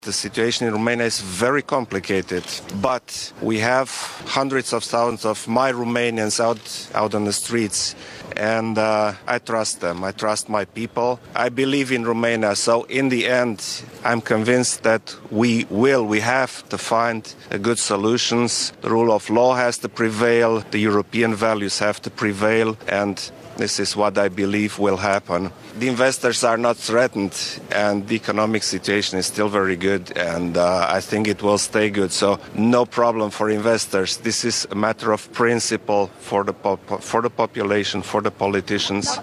“Este un summit important. Așteptările sunt ridicate. Trebuie să găsim o cale bună pentru Europa și un drum bun spre (summitul de la) Roma”, a declarat Klaus Iohannis, în limba engleză, răspunzând întrebărilor jurnaliștilor la sosirea la reuniunea Consiliului European din La Valletta.